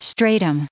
added pronounciation and merriam webster audio
931_stratum.ogg